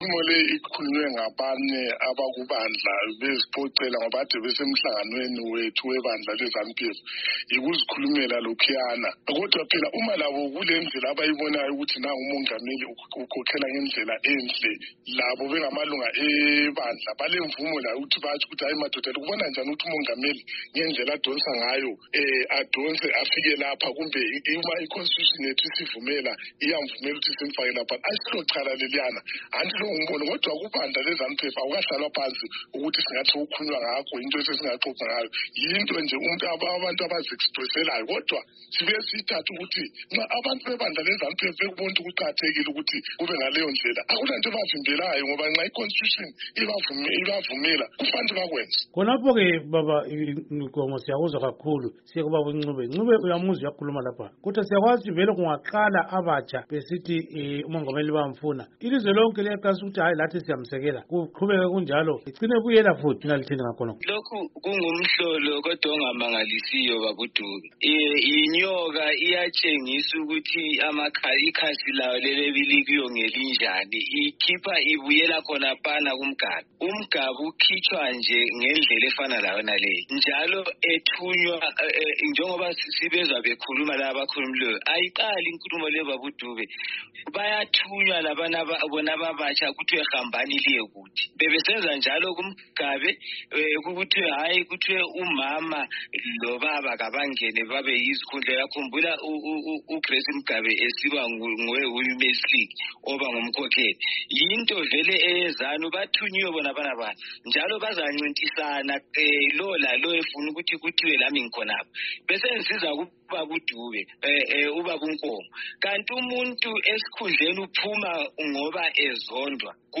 Ingxoxo Esiyenze loMnu.